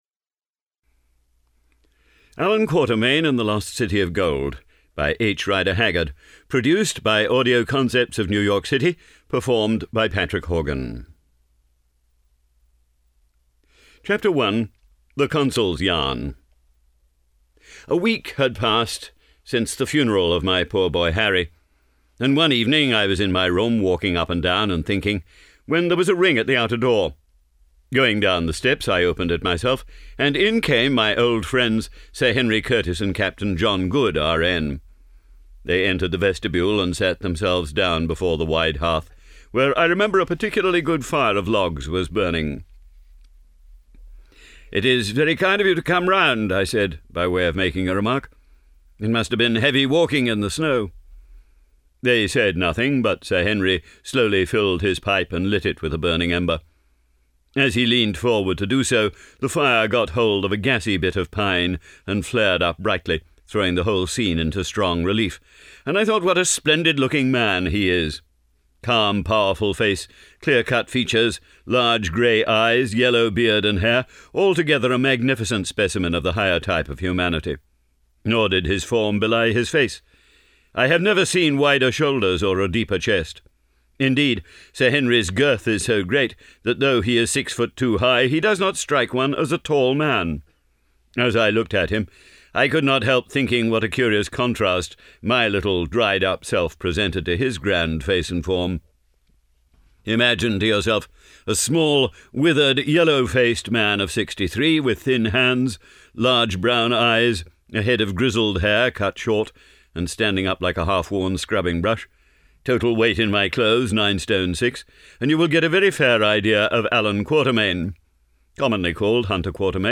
The Lost City of Gold audiobook mp3 d/l